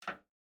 step_plastic.ogg